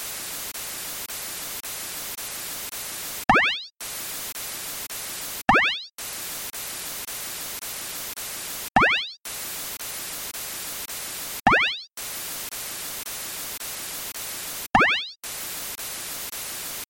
Comment associer les bips du fichier audio au code écrit sur la même page du logbook ?